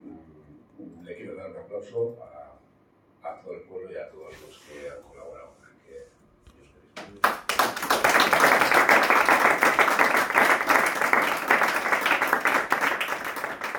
Todo el consistorio estalló en un fuerte aplauso al escuchar los datos que el equipo de gobierno presentó tras la recogida masiva, siguiendo las indicaciones de EUDEL, para ayudar al pueblo de Valencia afectado por la DANA.
Pleno-nov-24-Oion-aplausos-.m4a